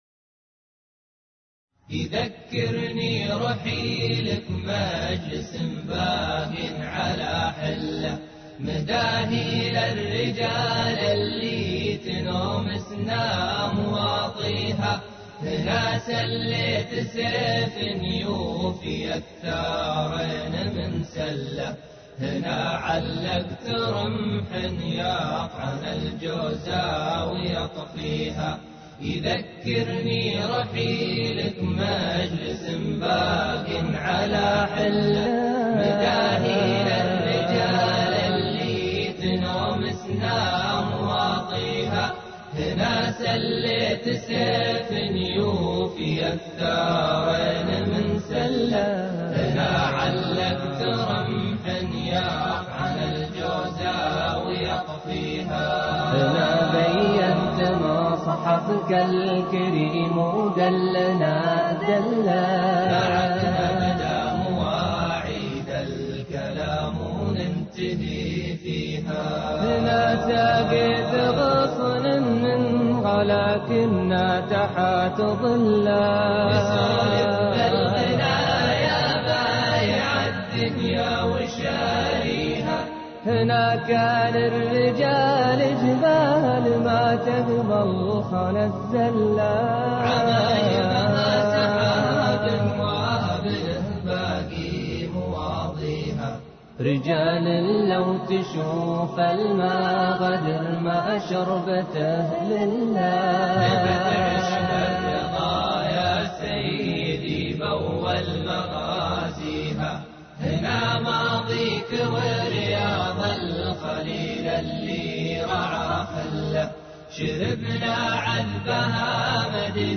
نشيدة